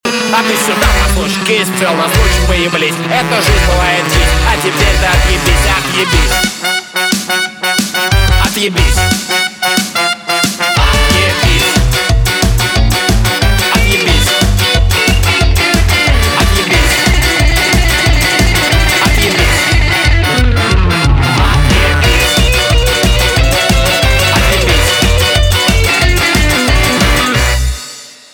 русский рок
барабаны , гитара , труба